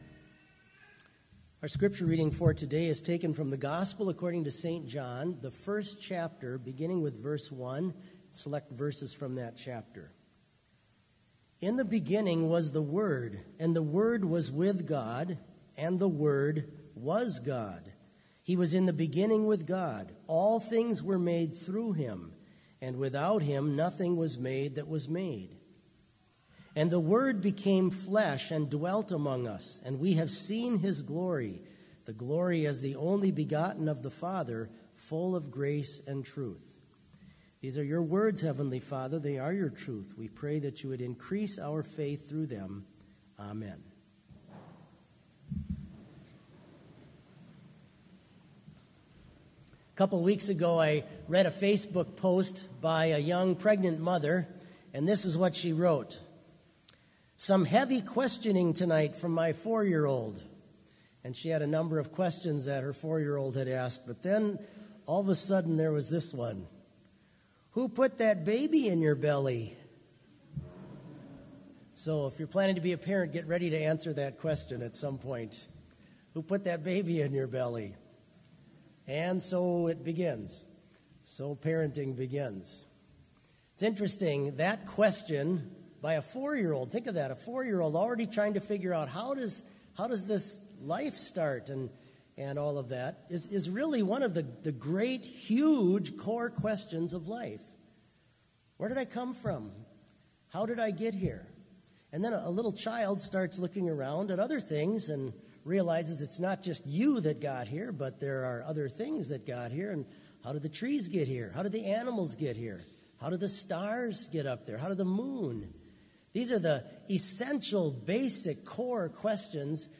Complete service audio for Chapel - March 1, 2019